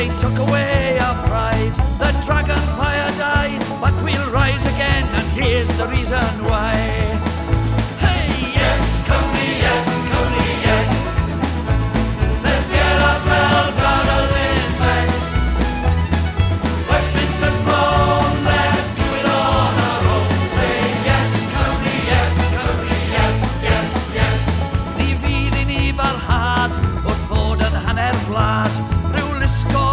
• Traditional Pop